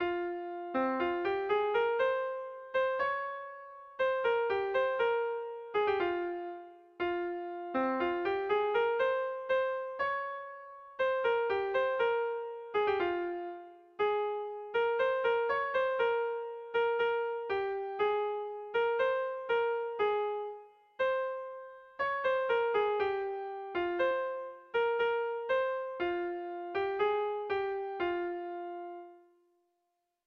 Erlijiozkoa
AABD